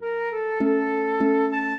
flute-harp
minuet10-5.wav